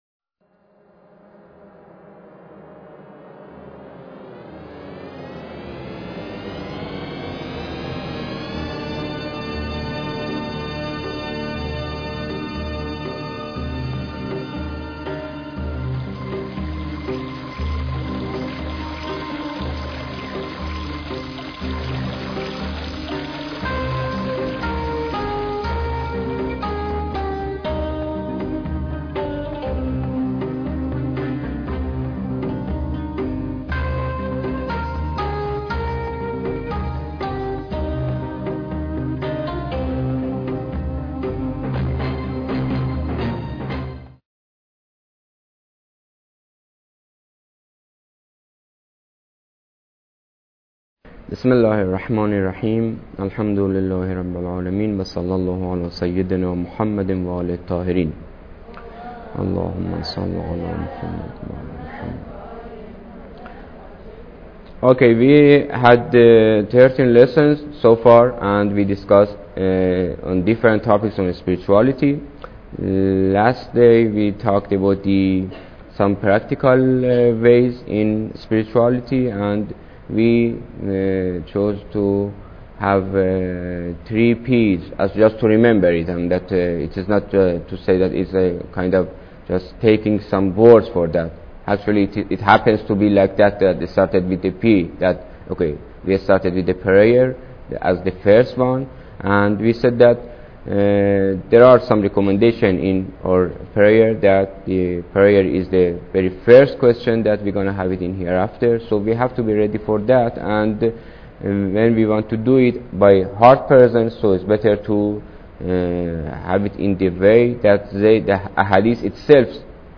Lecture_14